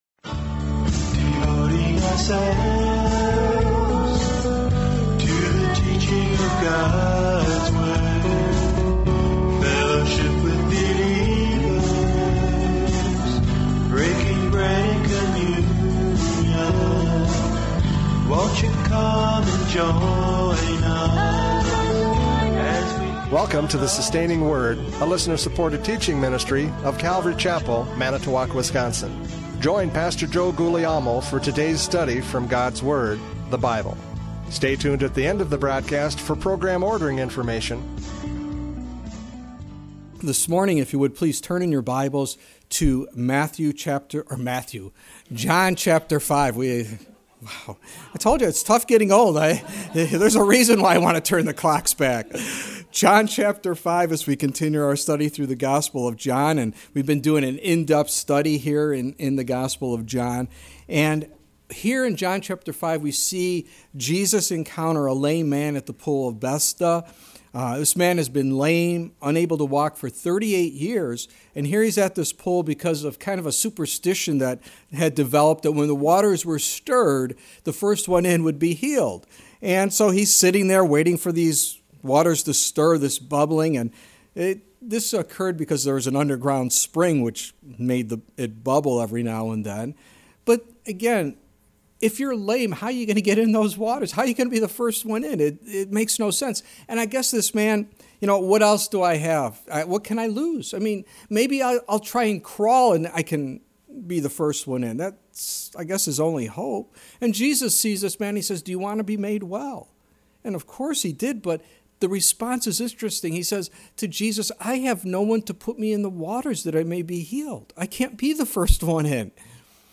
John 5:19-21 Service Type: Radio Programs « John 5:17-18 Equality in Creation!